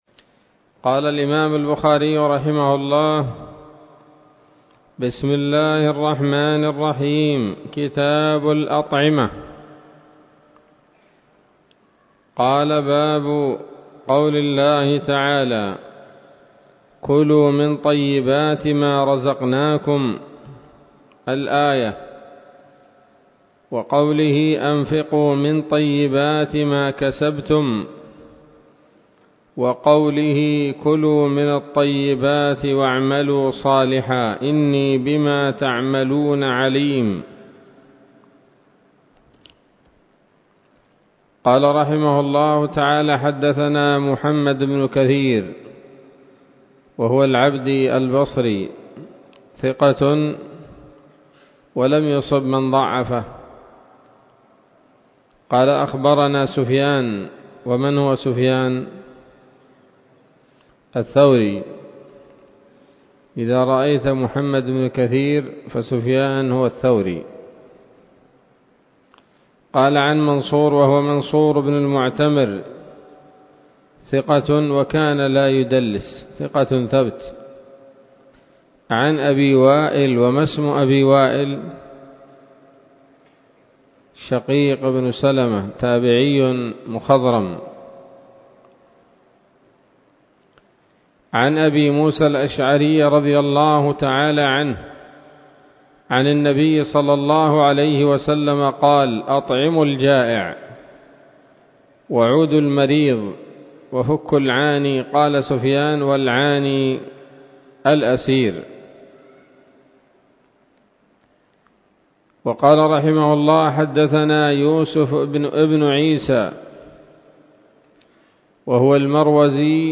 الدرس الأول من كتاب الأطعمة من صحيح الإمام البخاري